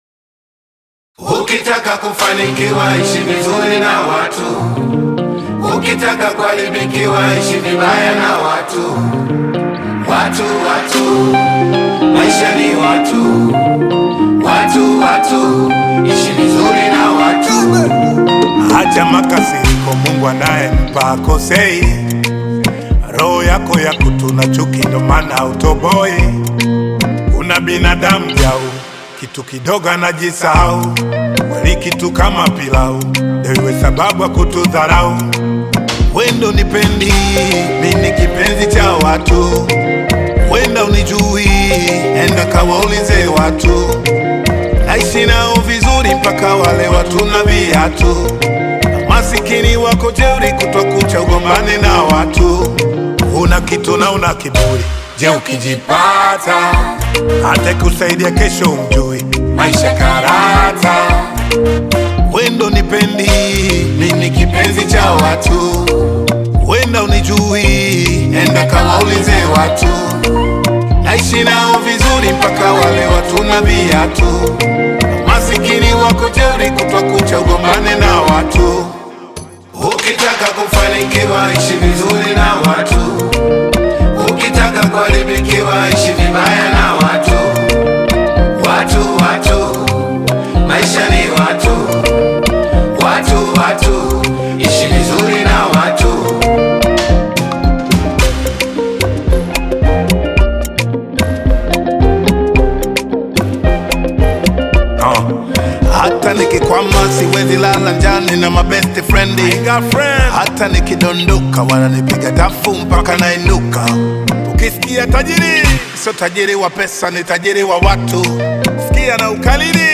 Tanzanian controversial rapper and lyrical heavyweight